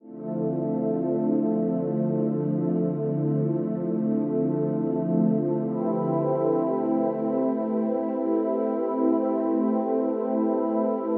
Tag: 86 bpm Ambient Loops Pad Loops 1.88 MB wav Key : Unknown